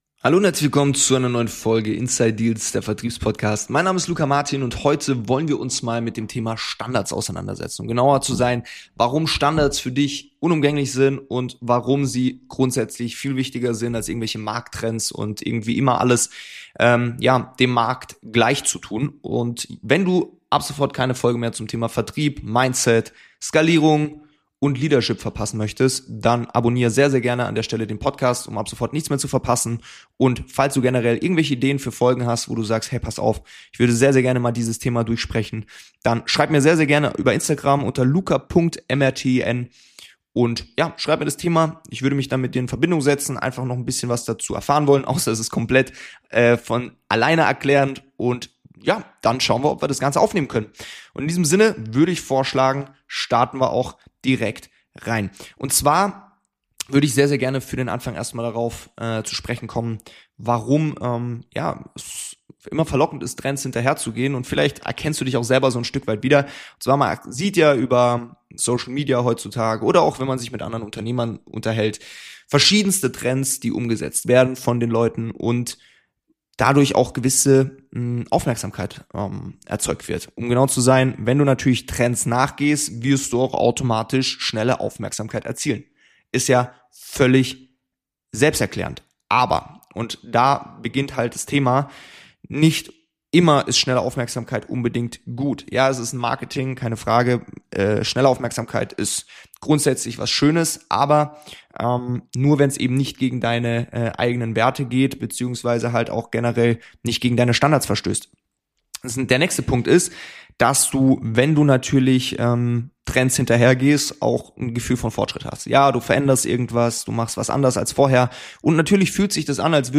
Eine ruhige, reflektierte Folge für Unternehmer und Führungskräfte, die nicht jedem Trend hinterherlaufen wollen, sondern ihr Business mit klaren Prinzipien und langfristiger Perspektive führen möchten.